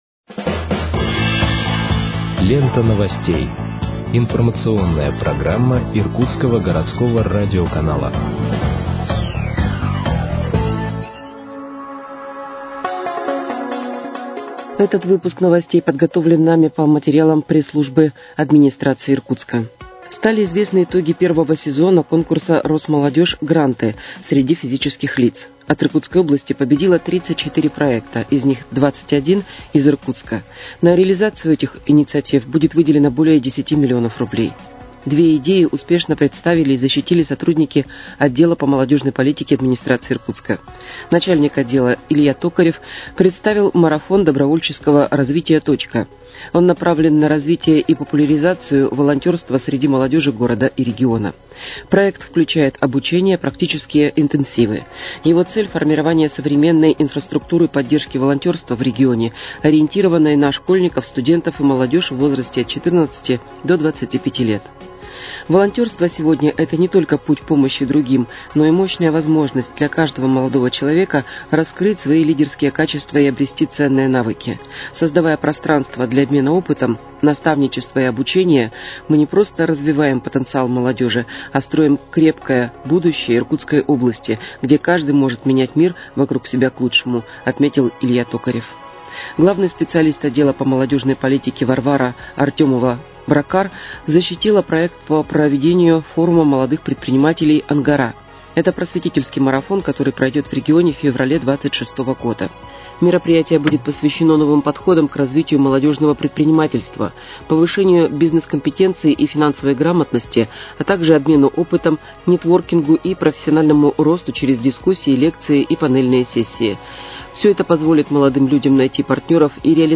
Выпуск новостей в подкастах газеты «Иркутск» от 2.06.2025 № 1